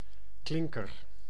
Ääntäminen
Synonyymit stuk steen vocaal baksteen tichel bouwsteen zelfklinker Ääntäminen Tuntematon aksentti: IPA: /ˈklɪŋ.kər/ Haettu sana löytyi näillä lähdekielillä: hollanti Käännös 1. гласна {f} (glásna) Suku: m .